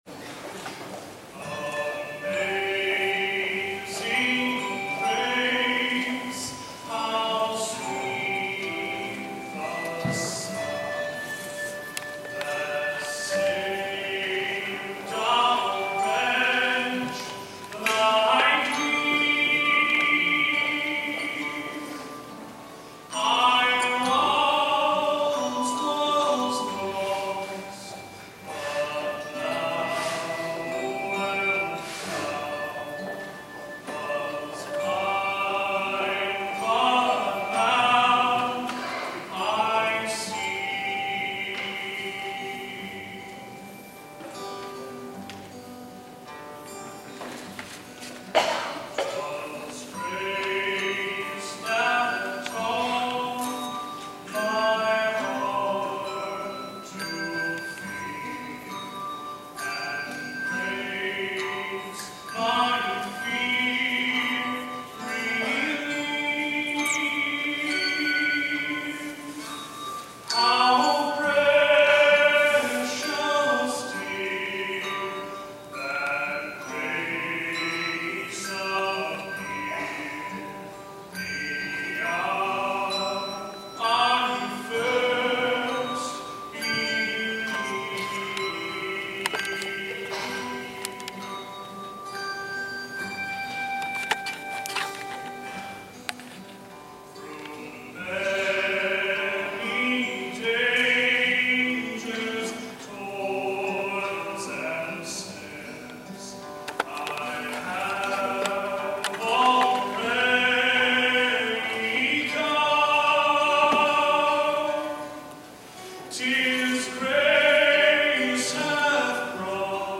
Musician Parent Sunday at AUMC
It was a guitar and handbell rendition of Amazing Grace